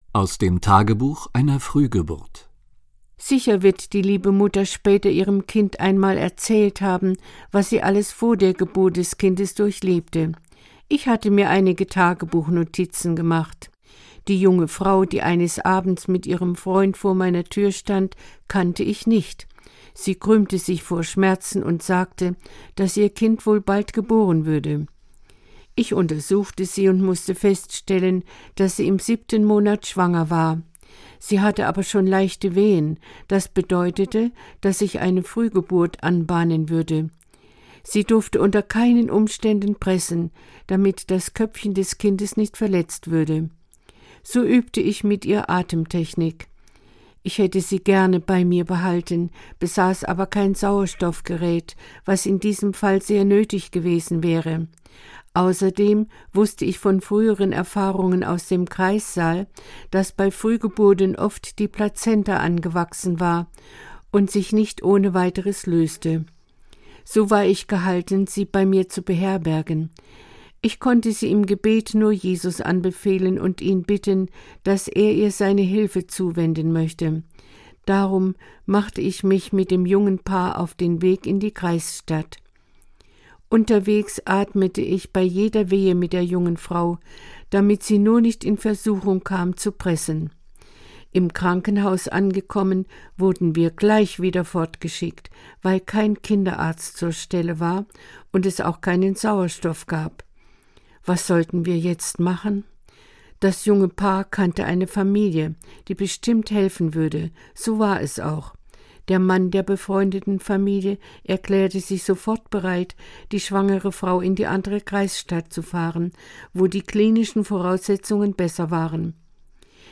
Hörbuch Neues von der Urwaldhebamme